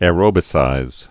(â-rōbĭ-sīz)